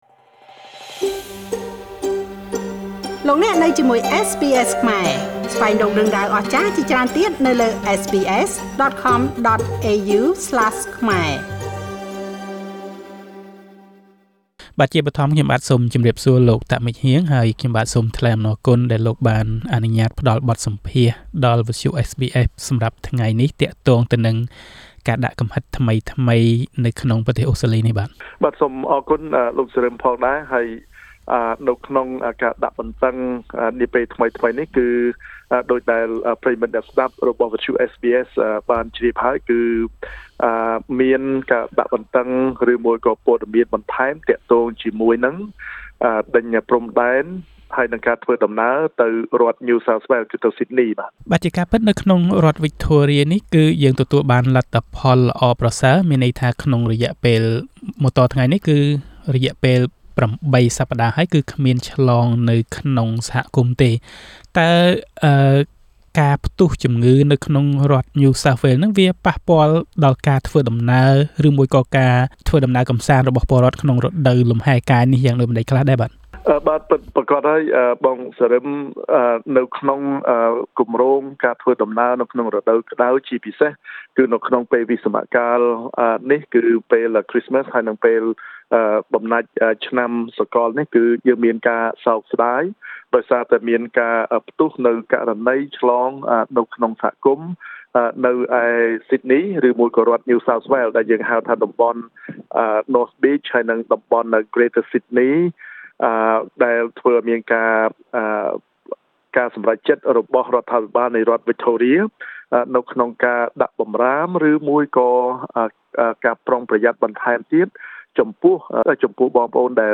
បទសម្ភាសន៍ស្តីពីការដាក់កំហិតនាពេលបច្ចុប្បន្ននៅអូស្ត្រាលី
លោក តាក ម៉េងហ៊ាង សមាជិកសភានៃរដ្ឋវិចថូរៀ។ Source: MP Heang Tak